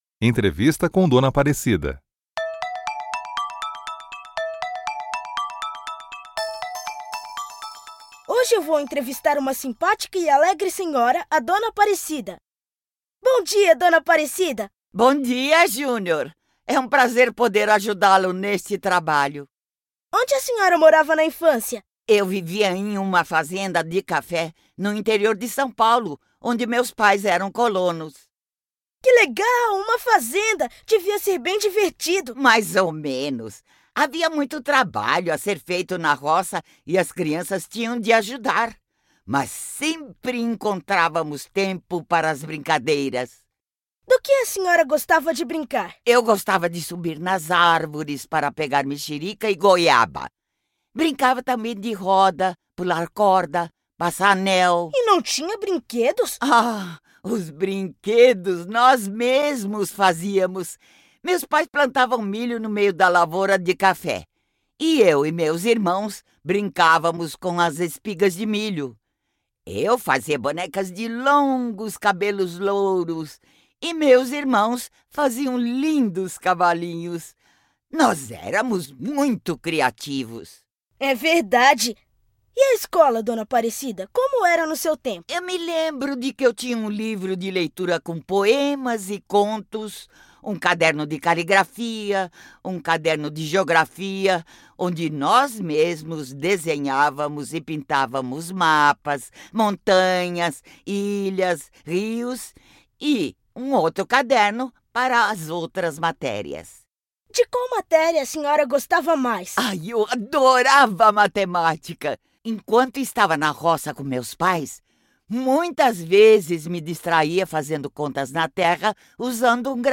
p_BUpor3_un02au_entrevista.mp3